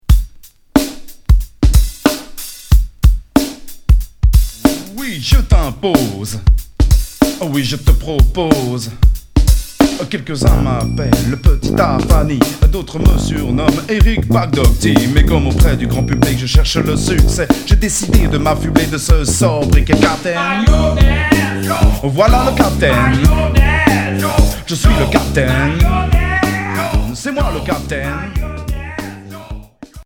Heavy funk